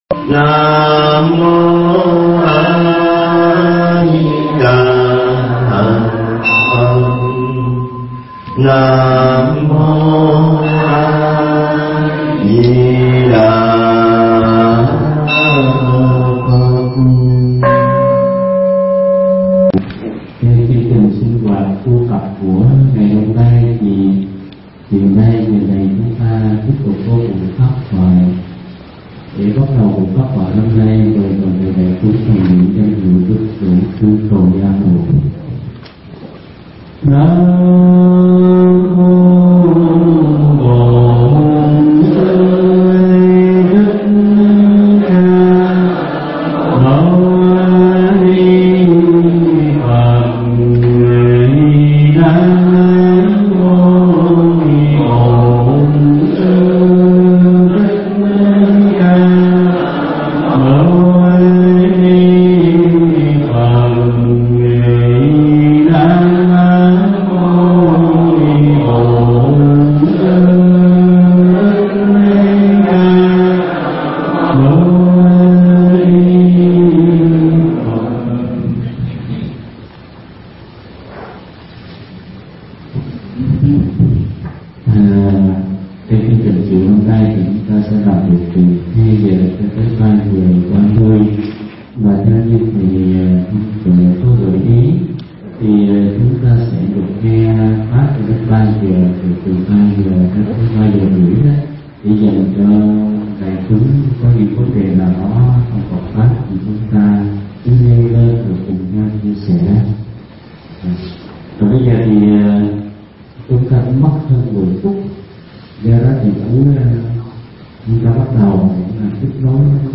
Pháp Thoại Giảng Tại Niệm Phật Đường Cực Lạc 2
Nghe Mp3 thuyết pháp Pháp Thoại Giảng Tại Niệm Phật Đường Cực Lạc 2